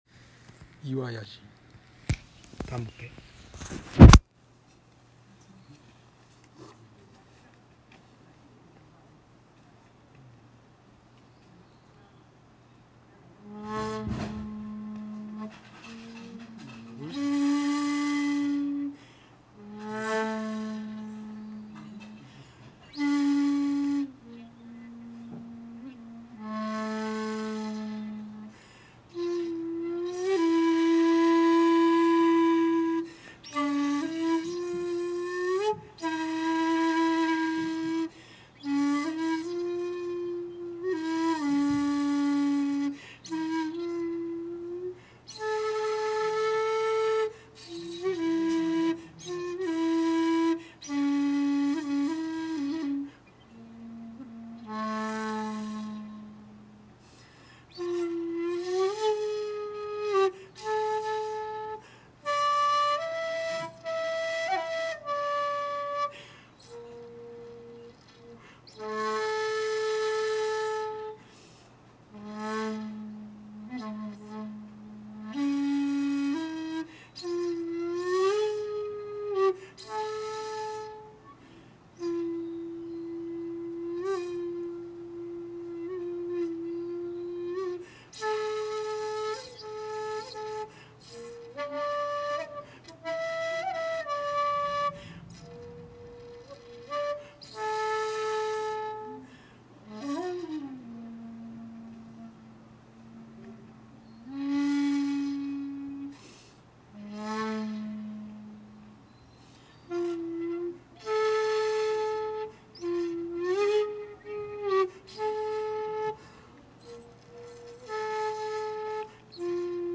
14:47に岩屋寺の本堂に到着。納経して、尺八を吹奏。
（写真⑥：岩屋寺にて尺八吹奏）
◆　（尺八音源：岩屋寺にて「手向」